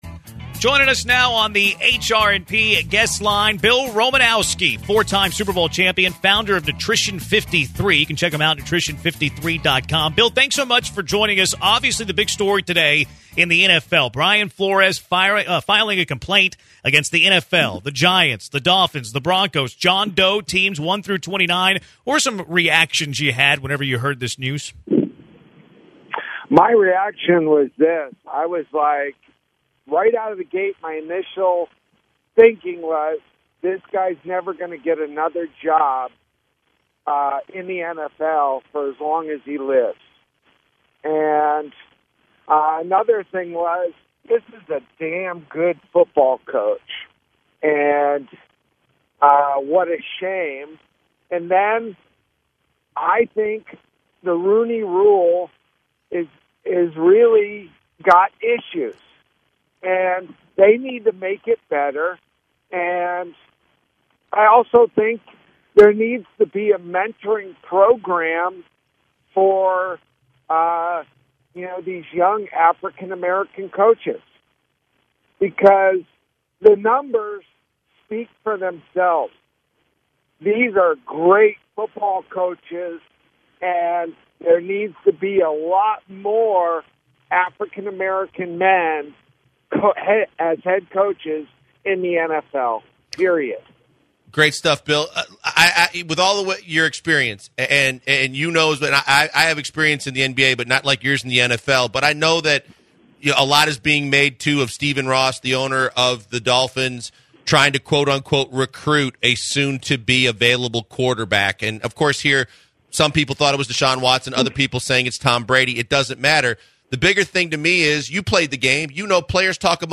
Former 4x Super Bowl Champion Linebacker Bill Romanowski calls in and weighs his opinion on the Brian Flores situation to start off the conversation